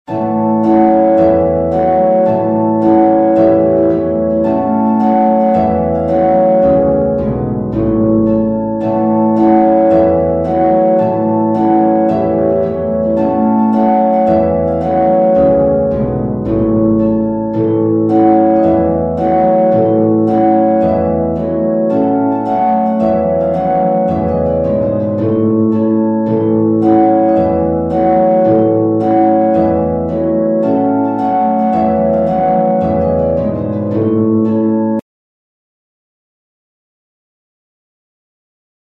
traditional
PEDAL HARP